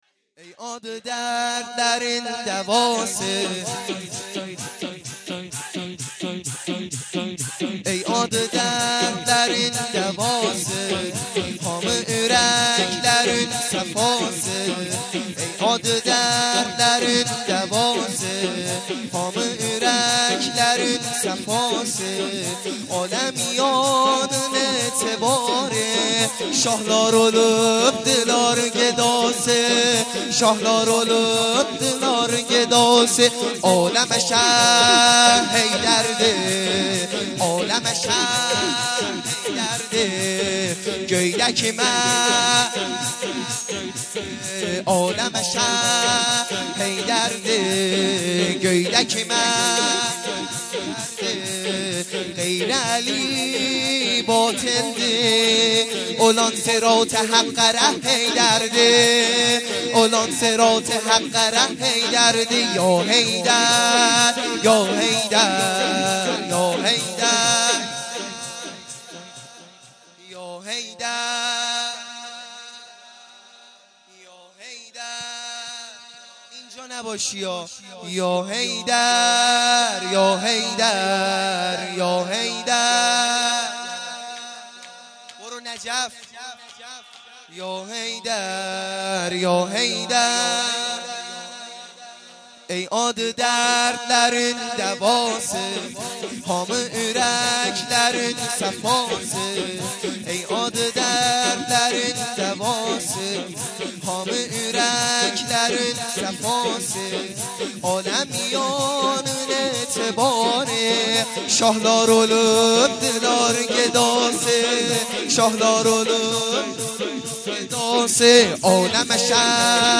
شور | ترکی